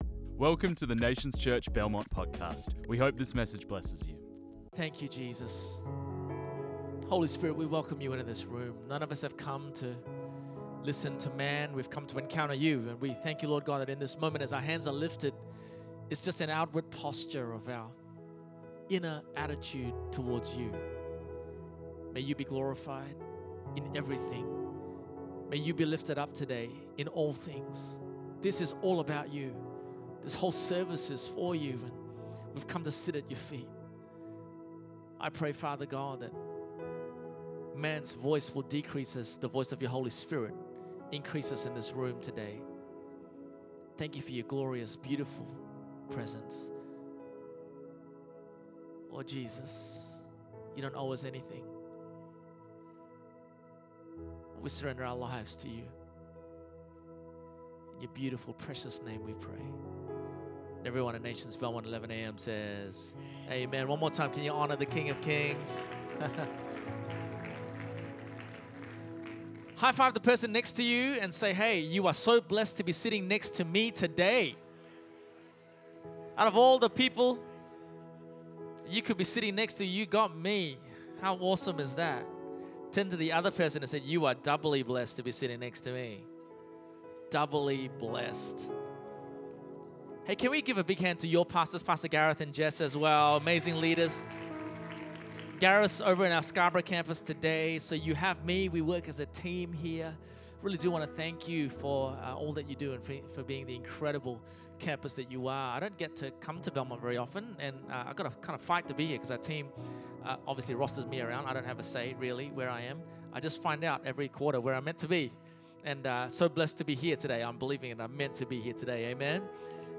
This message was preached on 01 June 2025.